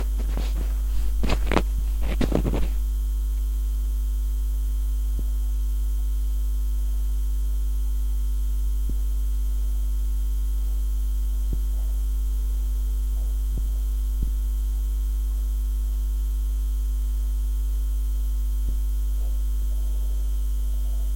How do you access “speak thinking” – sub vocal murmurs of what you are thinking.
This recording is a subtle as it can be using a piezoelectric contact microphone placing it near the adam’s apple. Just silent murmurs internally of what I was thinking.
29-aug-sub-vocal-murmurs.mp3